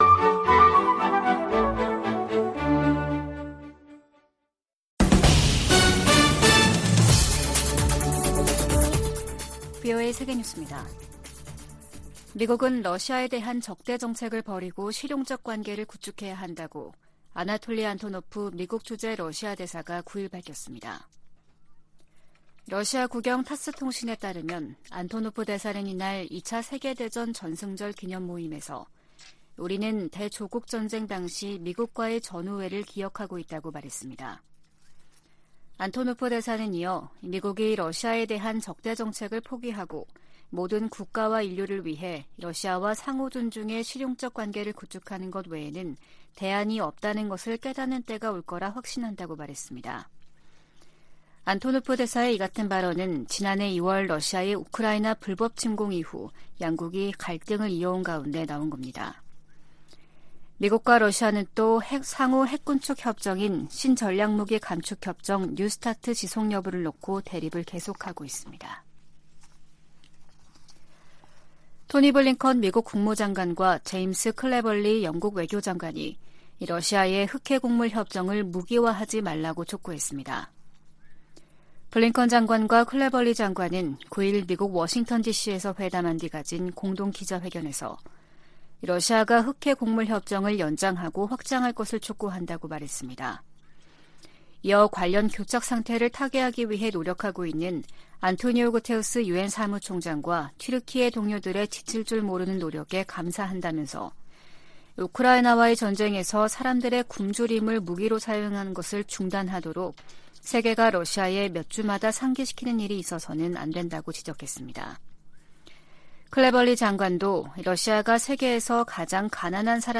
VOA 한국어 아침 뉴스 프로그램 '워싱턴 뉴스 광장' 2023년 5월 11일 방송입니다. 미 국무부는 ‘미한일 미사일 정보 실시간 공유 방침’ 관련 일본 언론 보도에 대해, 비공개 외교 대화 내용을 밝히지 않는다면서도, 3각 공조는 필수라고 강조했습니다.